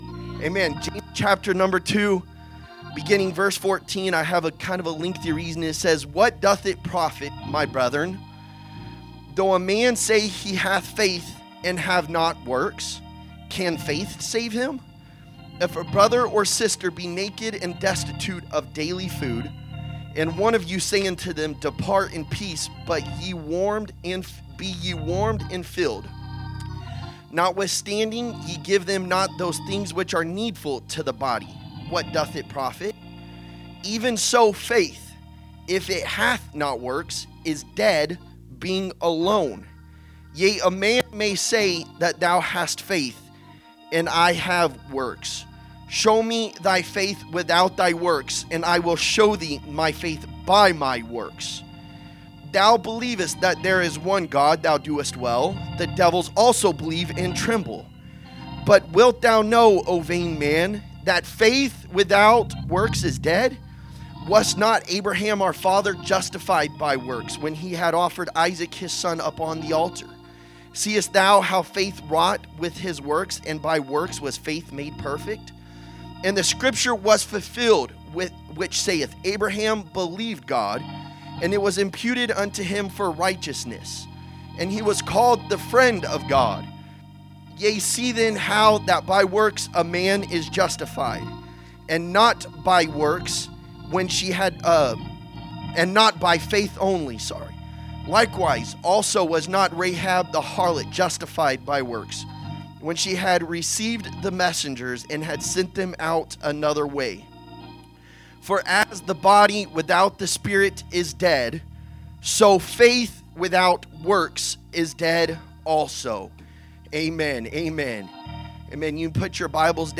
Wednesday Message